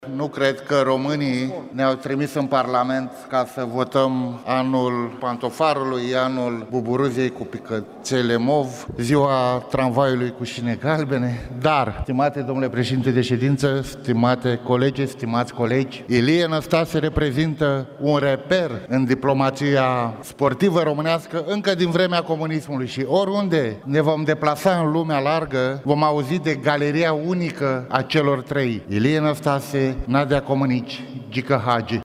Deputatul AUR Ciprian Paraschiv a vorbit în plen despre inițiativă.